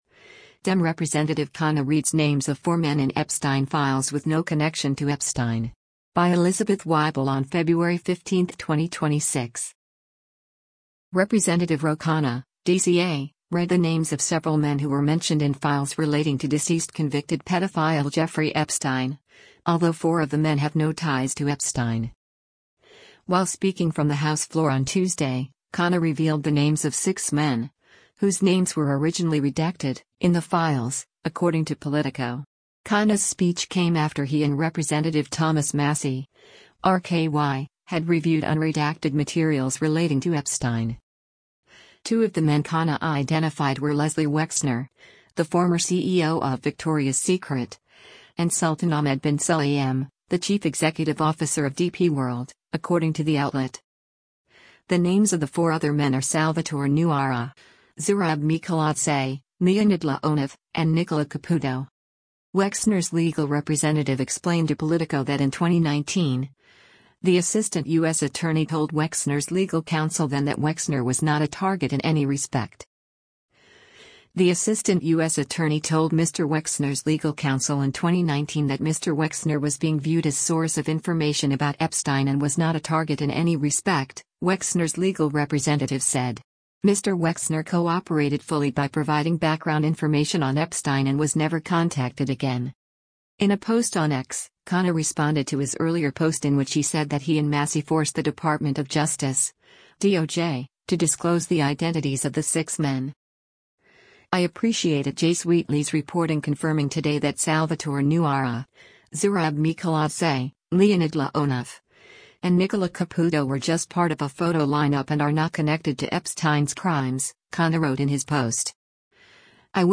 While speaking from the House floor on Tuesday, Khanna revealed the names of six men, “whose names were originally redacted,” in the files, according to Politico.